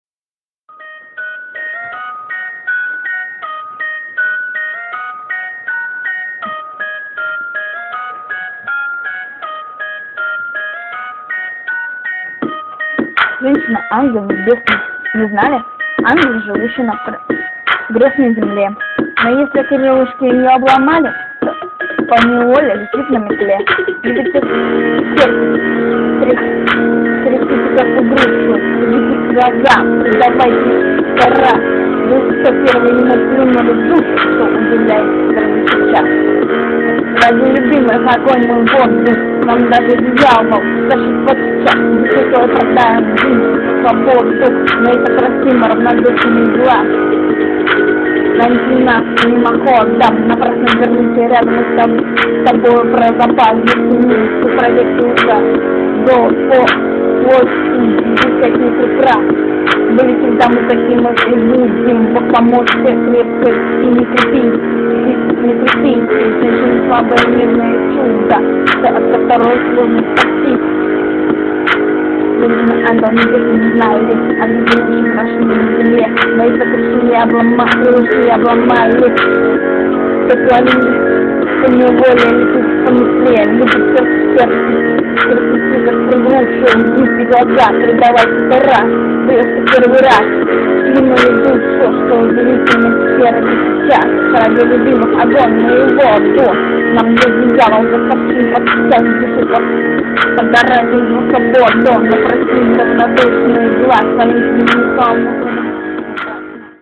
Песня хорошая но качество звука просто ужасно.